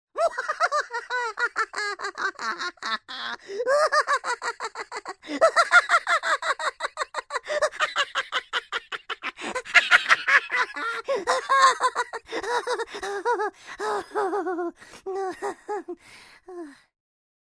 Звуки смеха